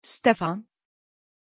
Aussprache von Stefan
pronunciation-stefan-tr.mp3